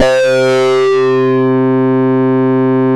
RESO JUPITER.wav